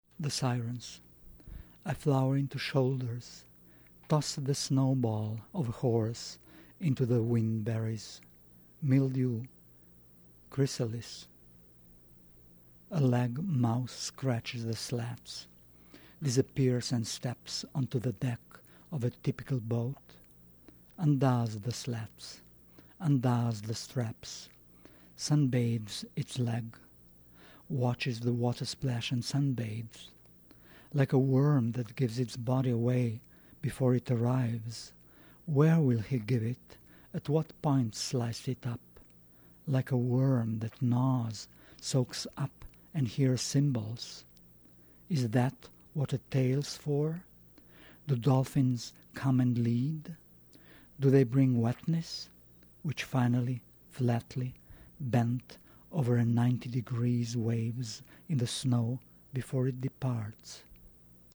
Slovenian poet Tomaž Šalamun reads "The Sirens" from his eleventh poetry collection in English, The Blue Tower, released by Houghton Mifflin Harcourt in October.